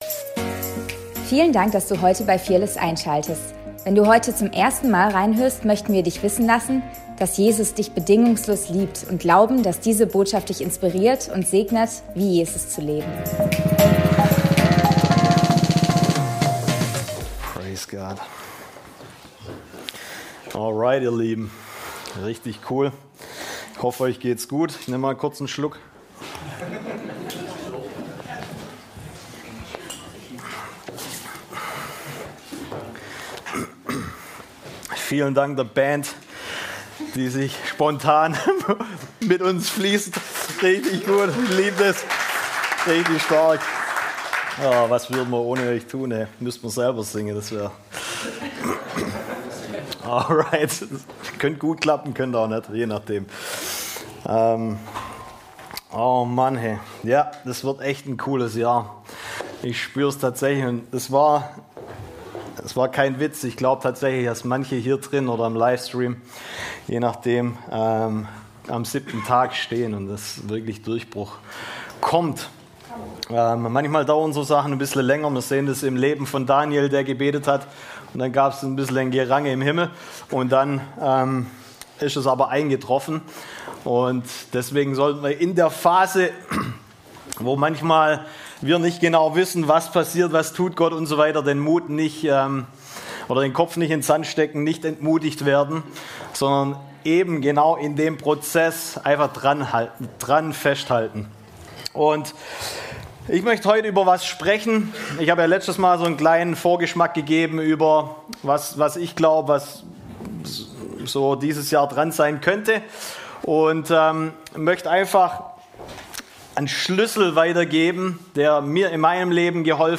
Predigt vom 18.01.2026